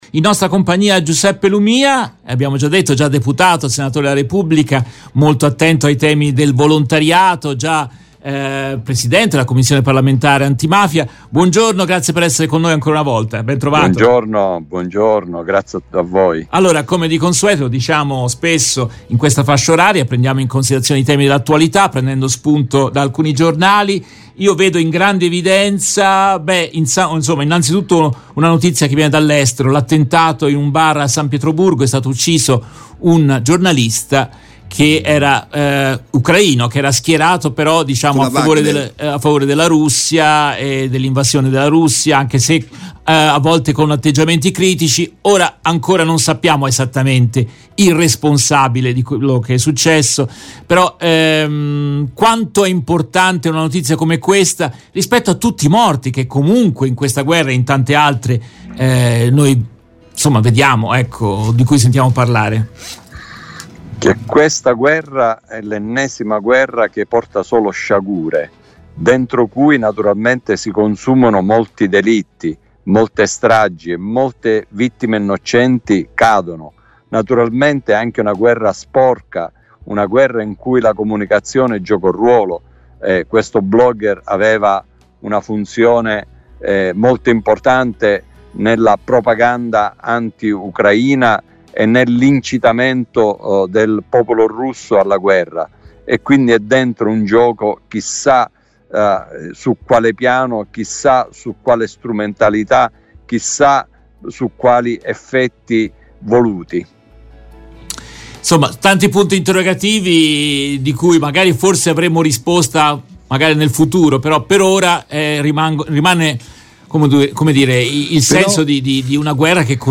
Nel corso della trasmissione in diretta del 03 aprile 2023